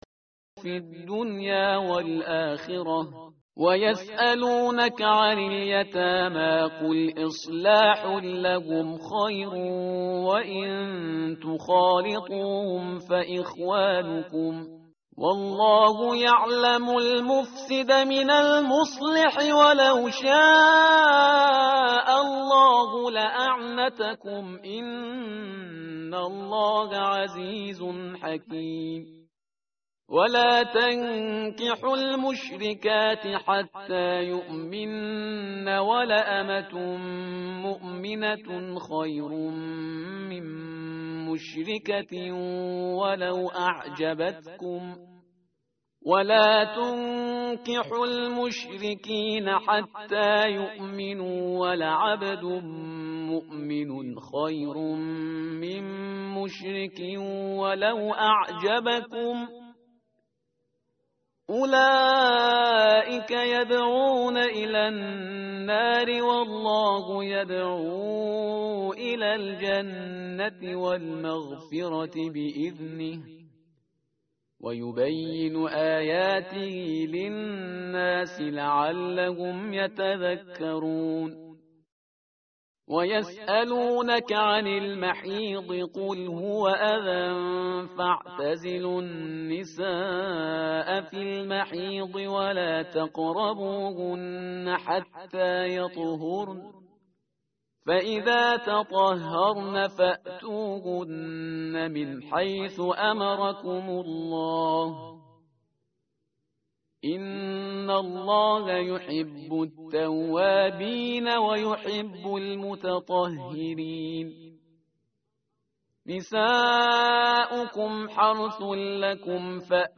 tartil_parhizgar_page_035.mp3